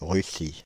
Russy (French pronunciation: [ʁysi]
Fr-Paris--Russy.ogg.mp3